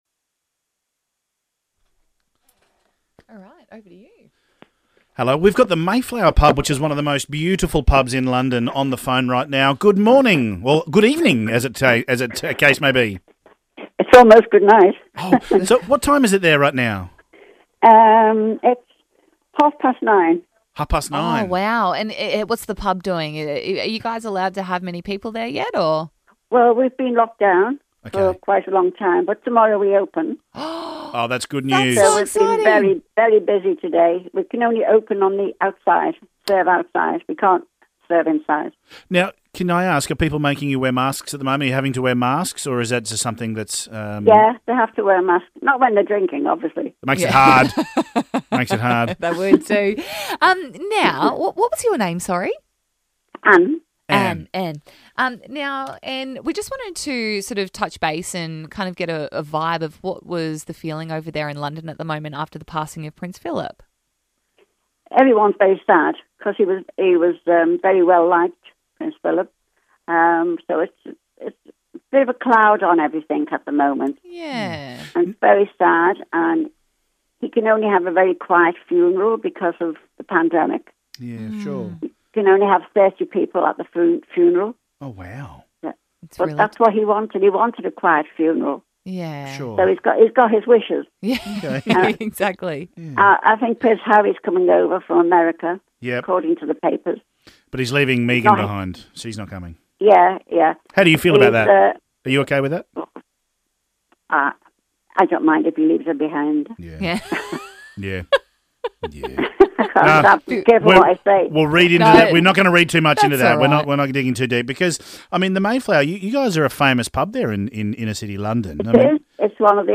rang the pub to gauge the reaction of Londoners from the iconic pub.